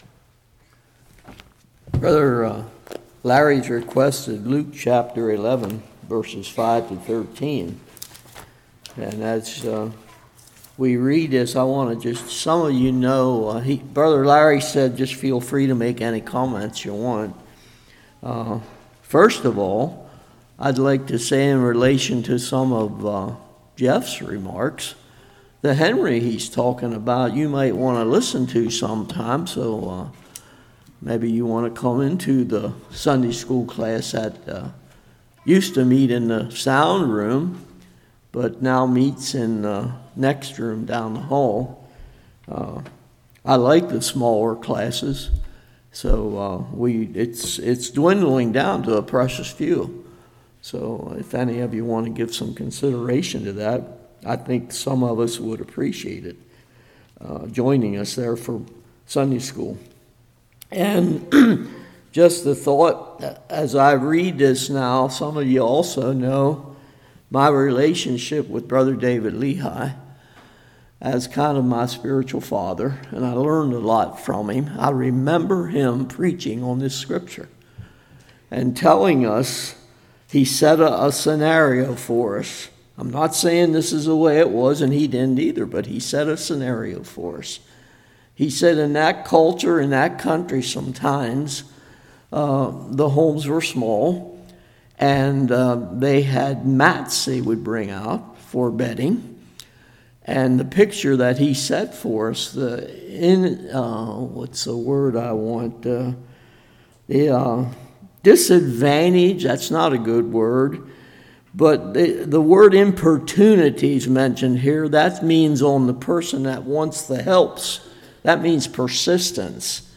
Luke 11:5-13 Service Type: Evening The Person Praying Has To Be Sincere.